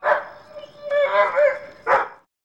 Index of /90_sSampleCDs/E-MU Producer Series Vol. 3 – Hollywood Sound Effects/Human & Animal/Dogs
WATCH DOG-R.wav